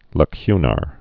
(lə-kynər)